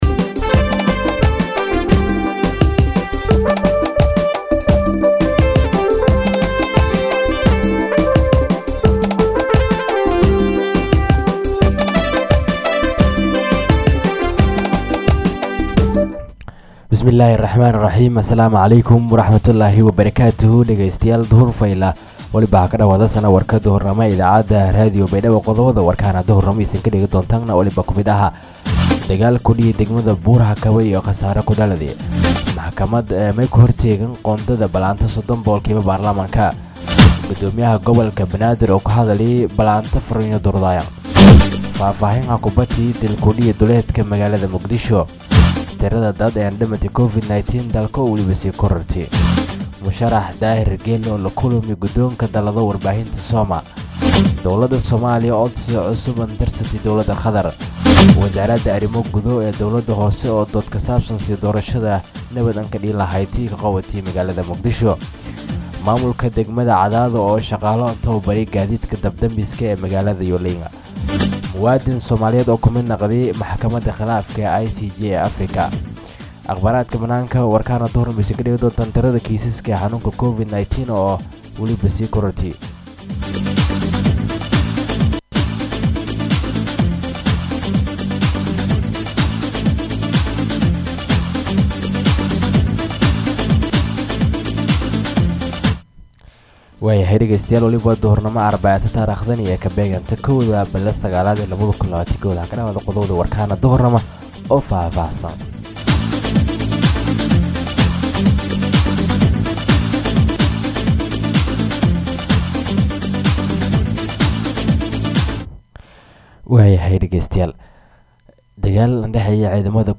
DHAGEYSO:-Warka Duhurnimo Radio Baidoa 1-9-2021